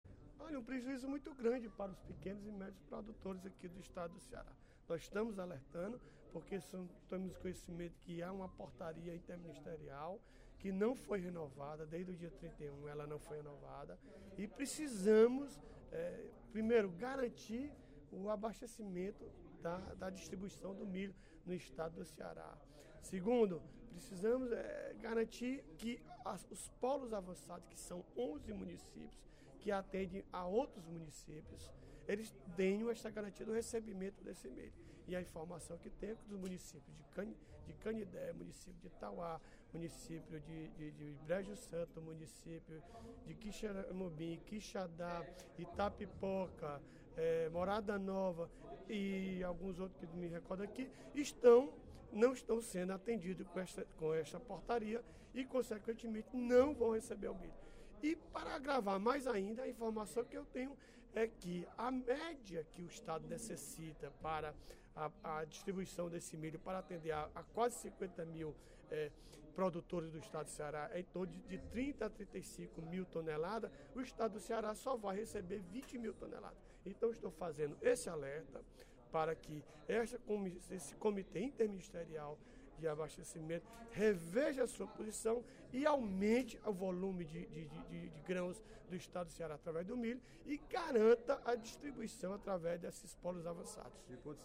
O deputado Odilon Aguiar (Pros) alertou sobre problemas no abastecimento de grãos de milho para pequenos e médios criadores do Interior no primeiro expediente da sessão plenária desta quarta-feira (03/06).